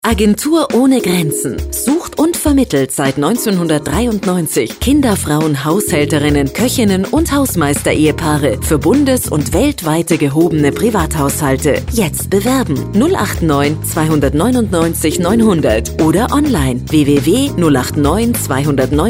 AOG Radio Werbe Spot
charivari_spot.mp3